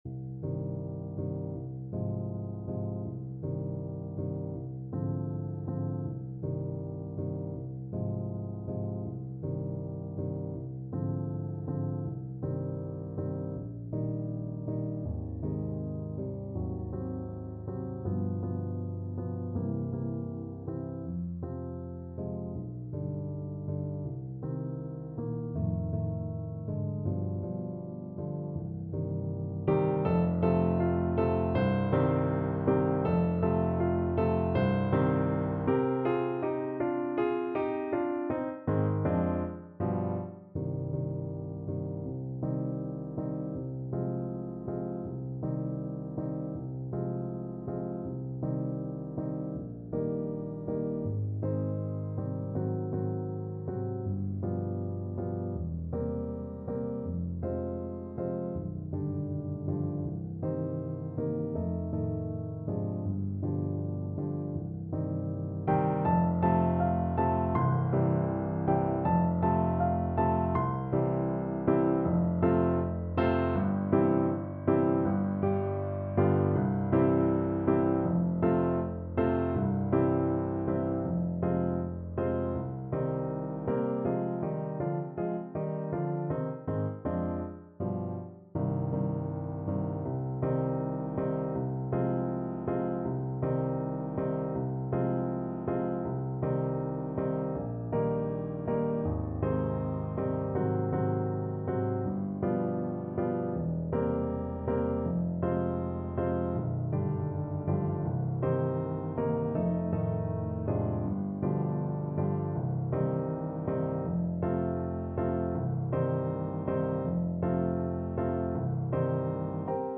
~ = 100 Andante
2/4 (View more 2/4 Music)